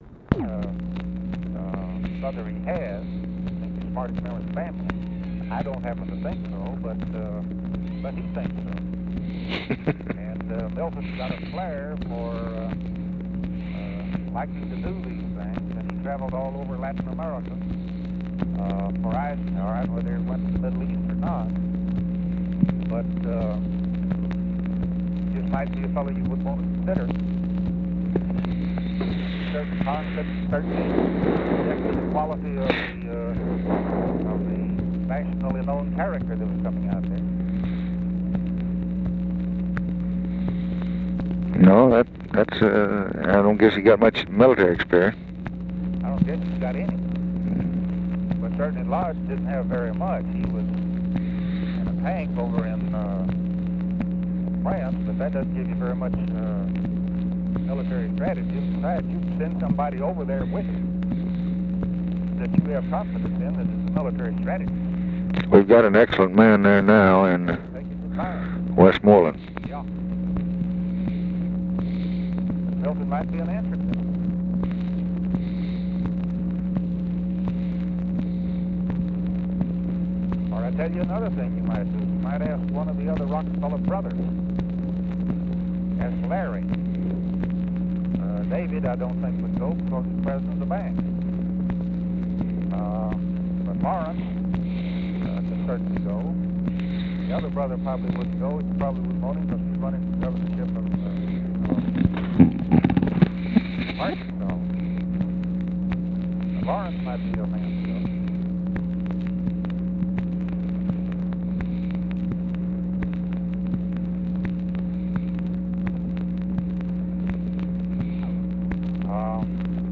Conversation with ROBERT ANDERSON, June 18, 1964
Secret White House Tapes